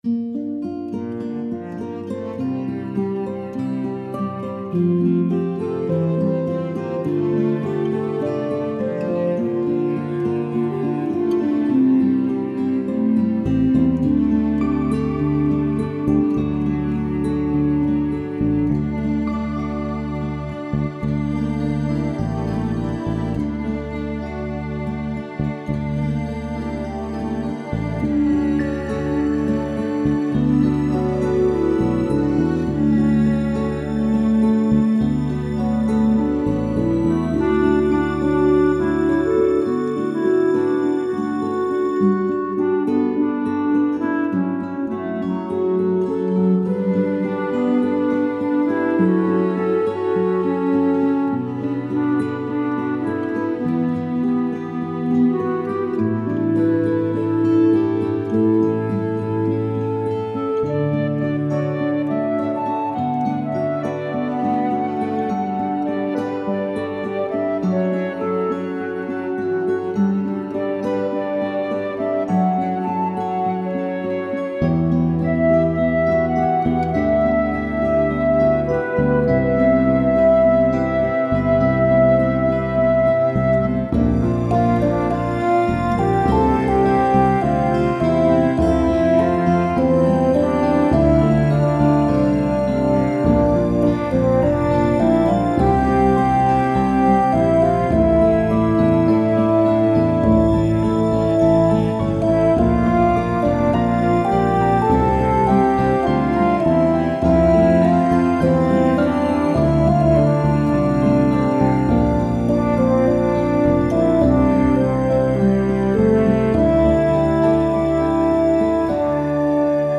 Initially, I worked with an arranger to create these lush arrangements.
1-never-gone-away-instrumental.mp3